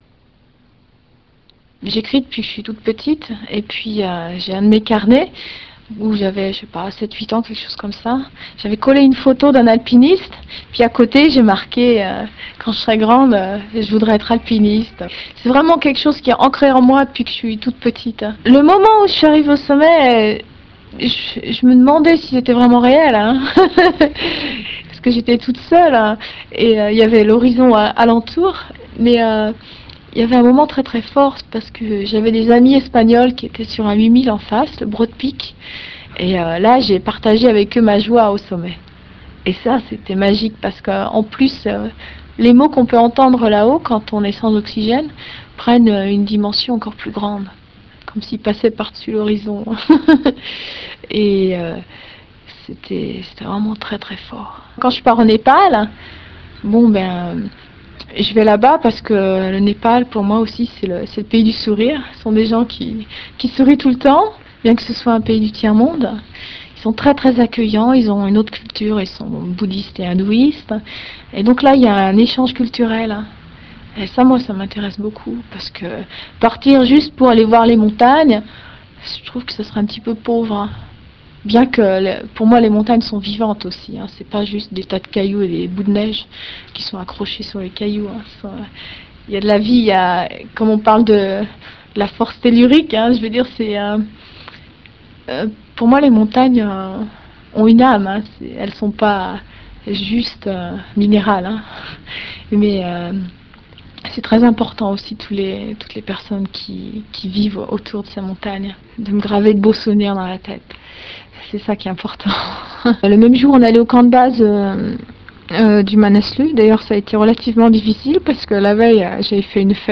VIIIe Festival de l'Aventure 1997
Alpiniste
Interview de Chantal